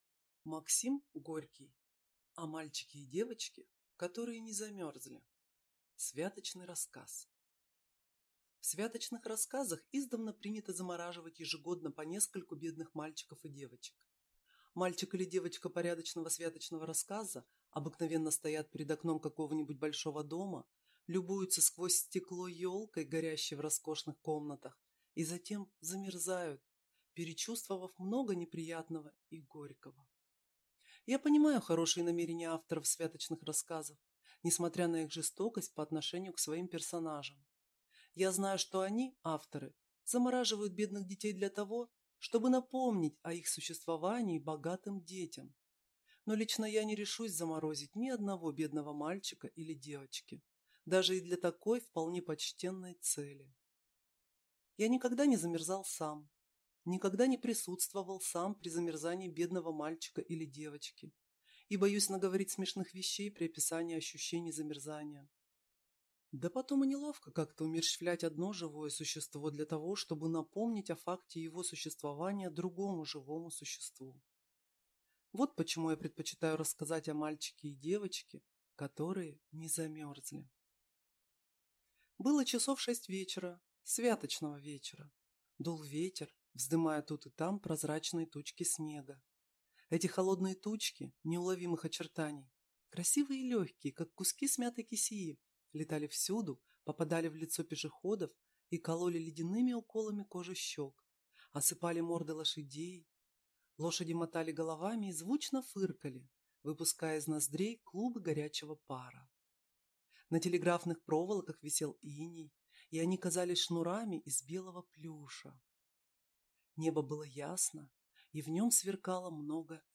Аудиокнига О мальчике и девочке, которые не замёрзли | Библиотека аудиокниг